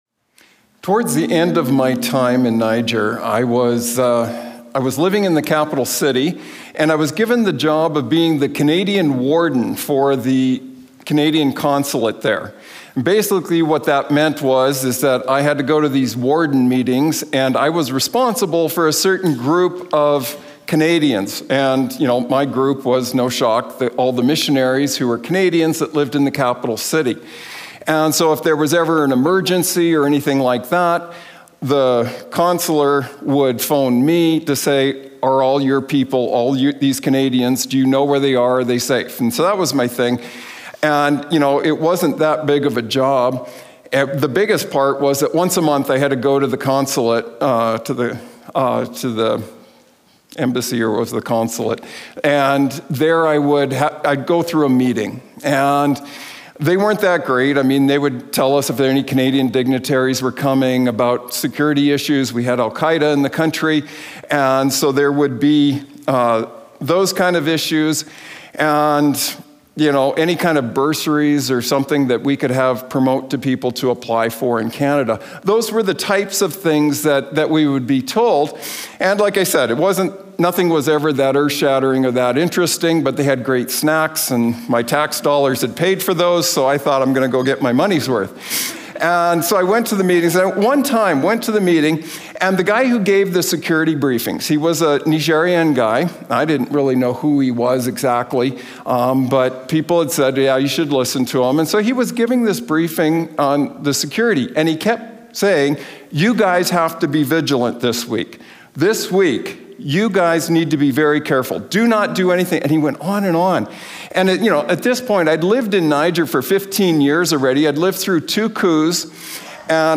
We welcome you to join us every week for a new Community Chapel service here at Prairie College.